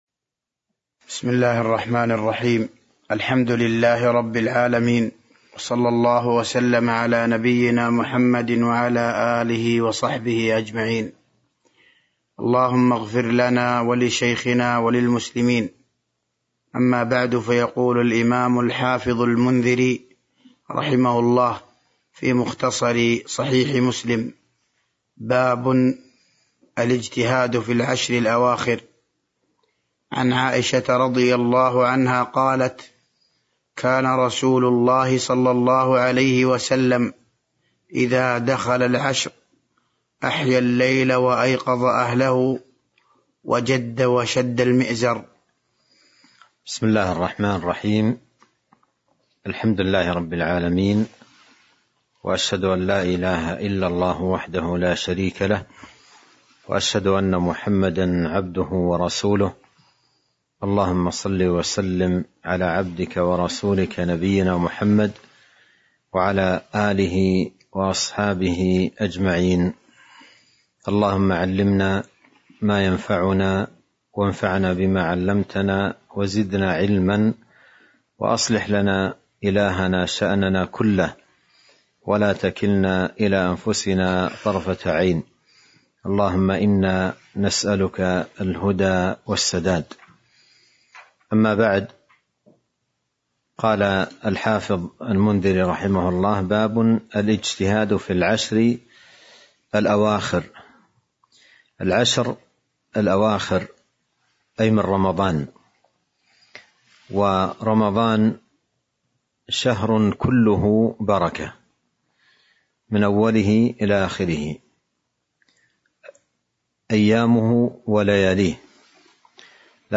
تاريخ النشر ٢٣ شعبان ١٤٤٢ هـ المكان: المسجد النبوي الشيخ